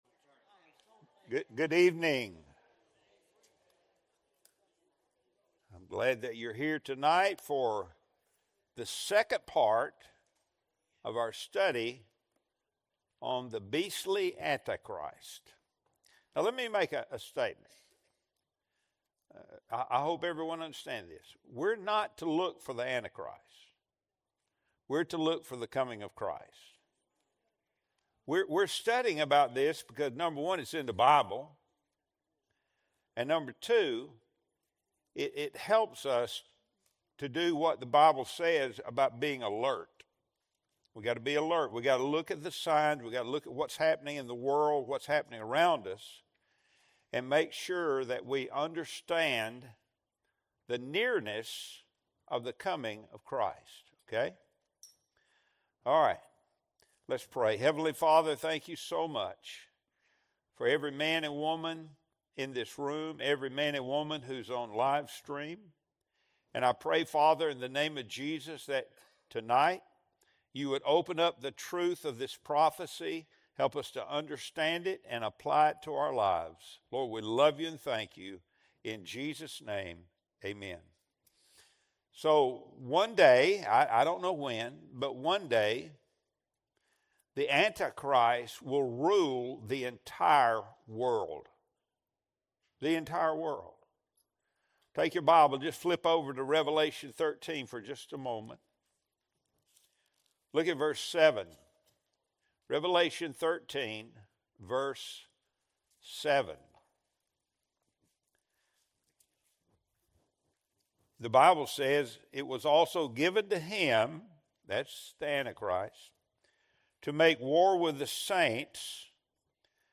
Wednesday Bible Study Series | February 18, 2026